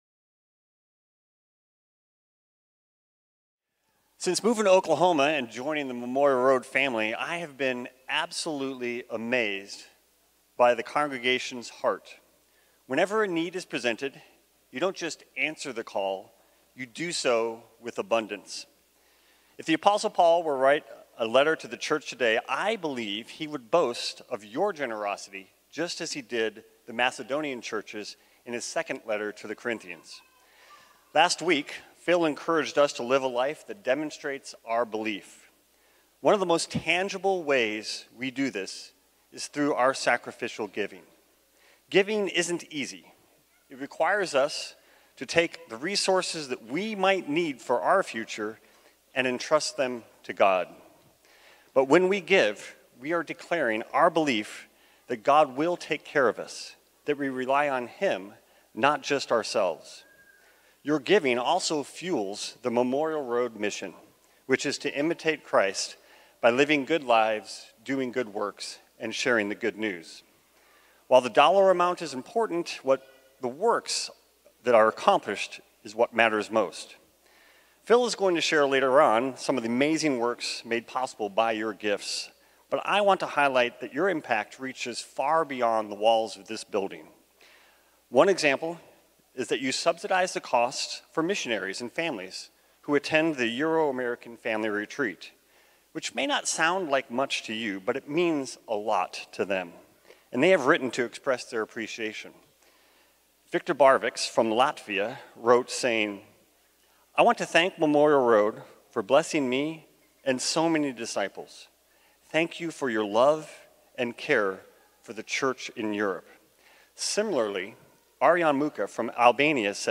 Series: Stand Alone, Sunday Morning